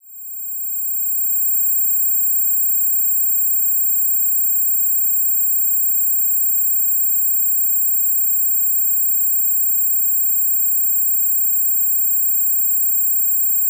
Ringing in ears2
atmosphere ears effect explosion movie noise pad ringing sound effect free sound royalty free Sound Effects